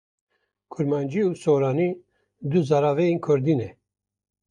/kʊɾmɑːnˈd͡ʒiː/